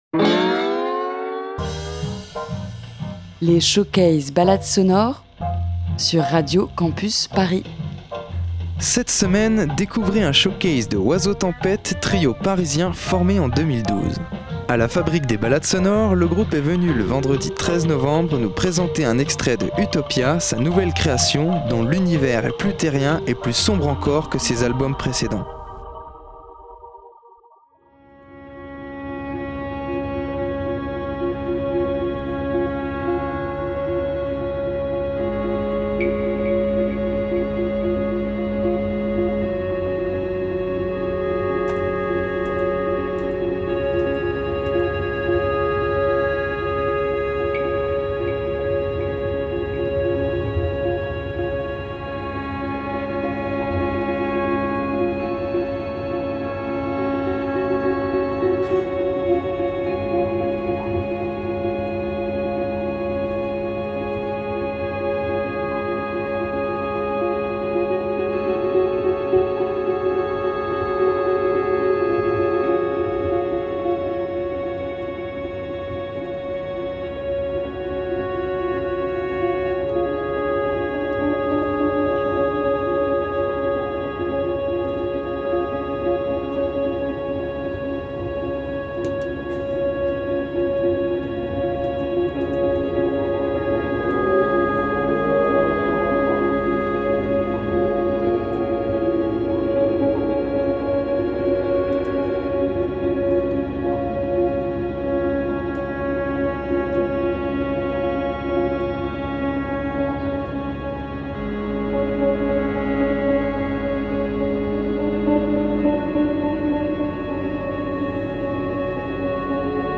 Duo, trio et parfois plus, de rock libre.
Ici en showcase tout improvisé